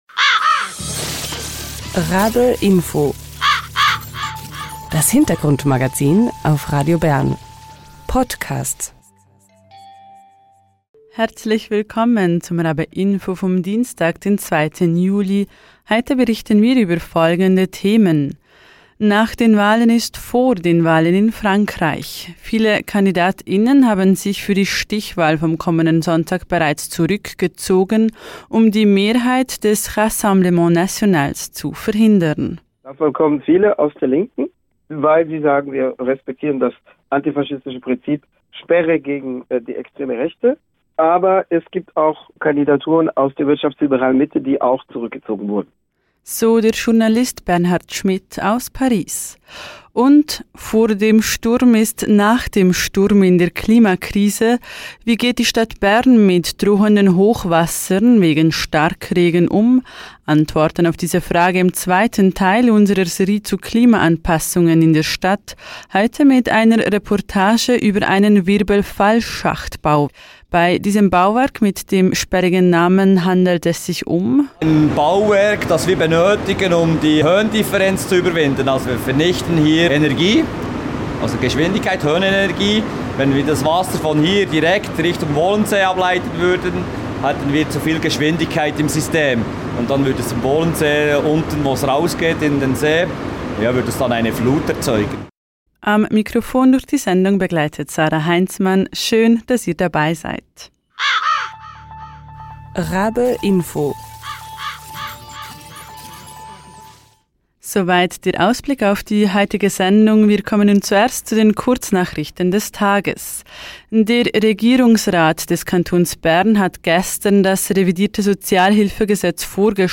Antoworten auf diese Frage im zweitel Teil unserer Serie zu Klimaanpassungen in der Stadt, heute mit einer Reportage über ein Wirbelfallschacht-Bauwerk.